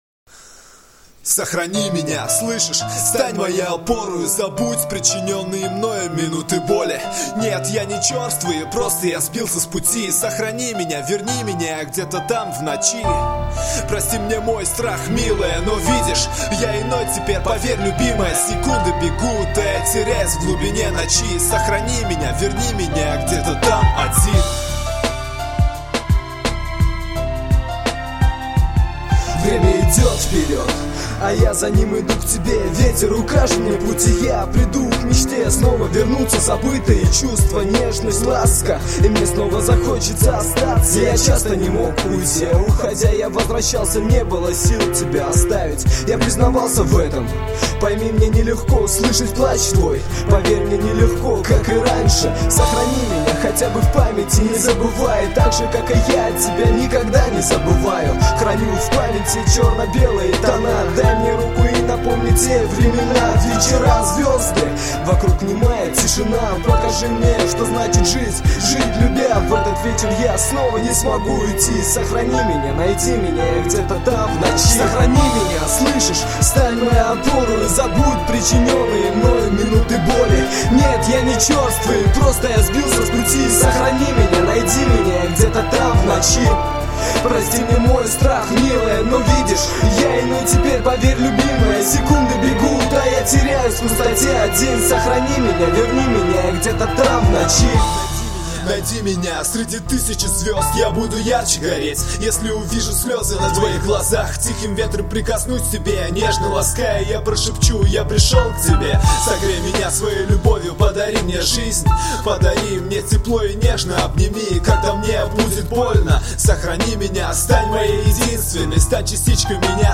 Рэп (46715)